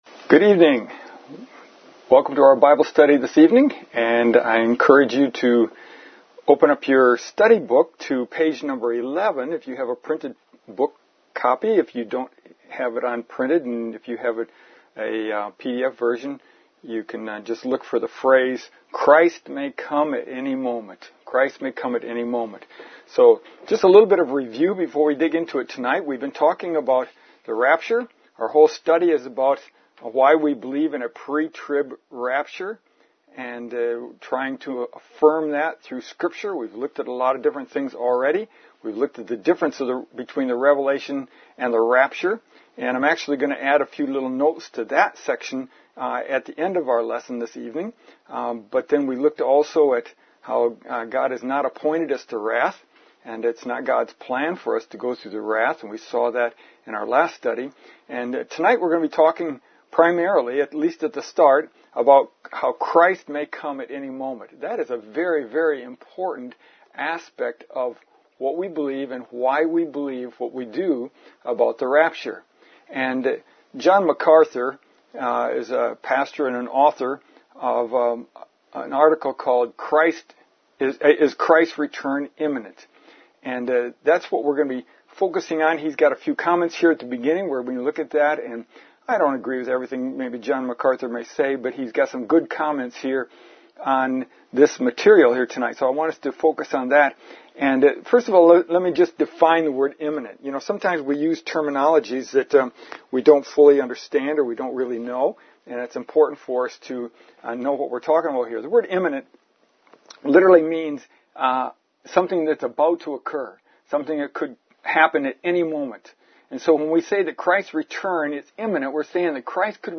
Pre-Tribulation Rapture Affirmed – Lesson 4